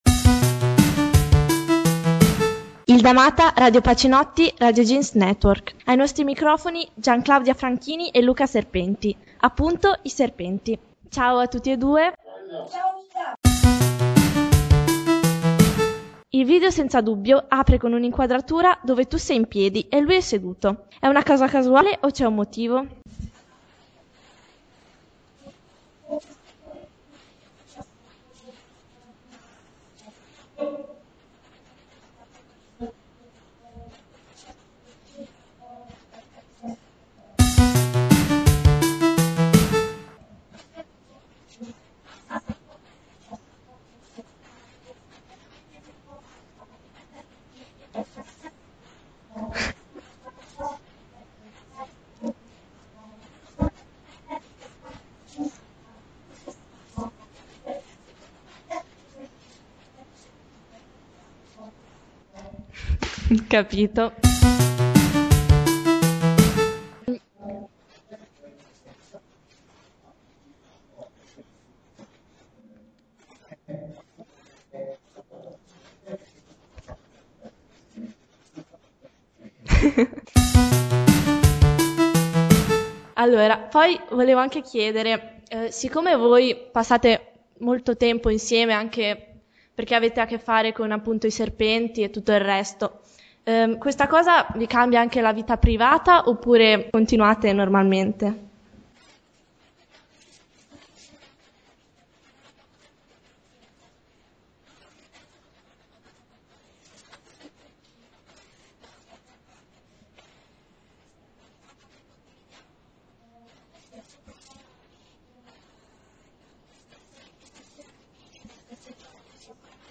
Intervista a i serpenti (gruppo musicale di rilevanza nazionale)